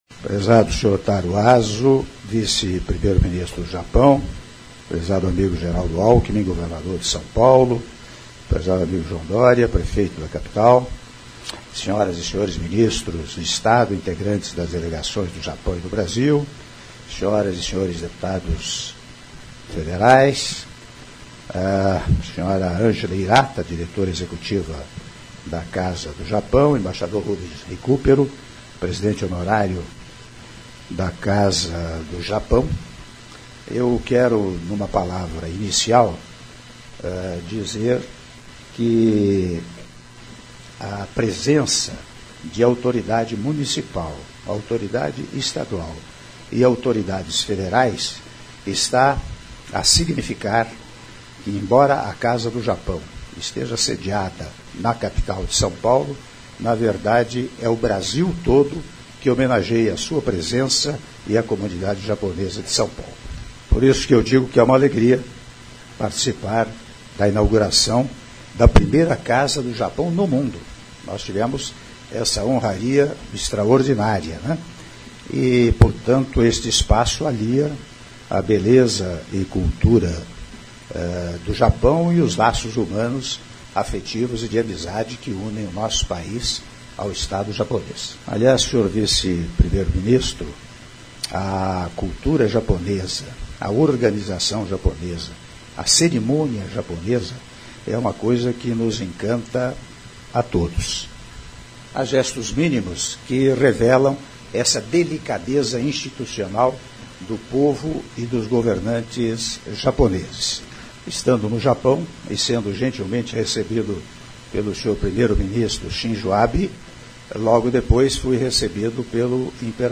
Áudio do discurso do Presidente da República, Michel Temer, durante cerimônia de abertura da Casa Japão São Paulo - São Paulo/SP (05min)